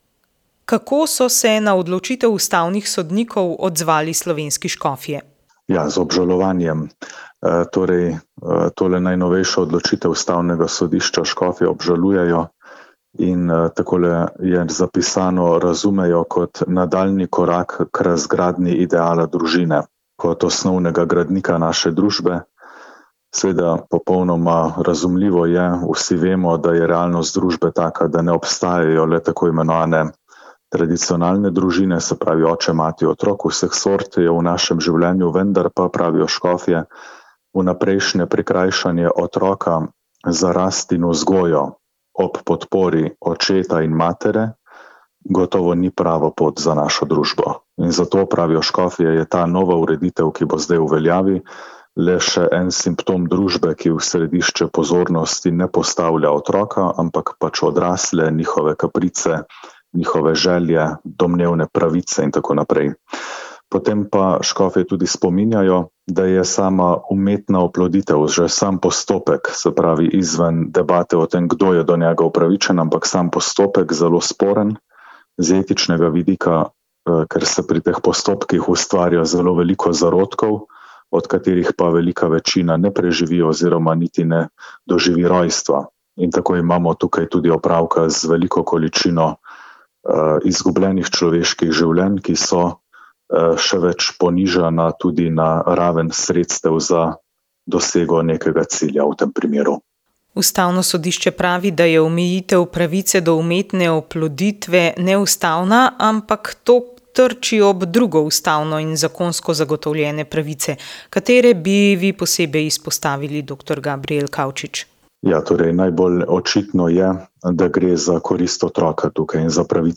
Več o dogajanju na podnebni konferenci v Bakuju pa v pogovoru s klimatologinjo dr. Lučko Kajfež Bogataj.